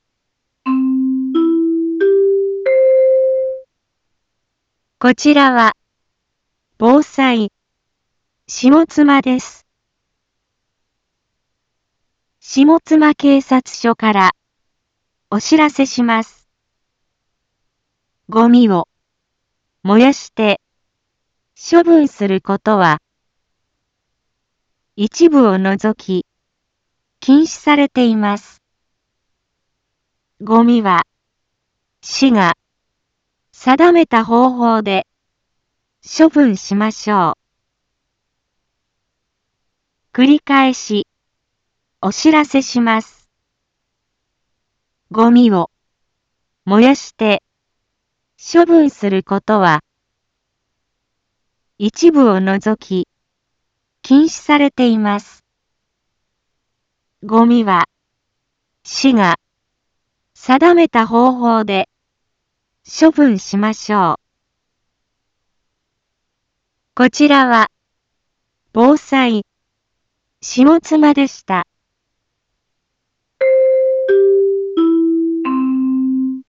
一般放送情報
Back Home 一般放送情報 音声放送 再生 一般放送情報 登録日時：2024-08-23 10:01:21 タイトル：ごみの野焼き禁止（啓発放送） インフォメーション：こちらは、ぼうさい、しもつまです。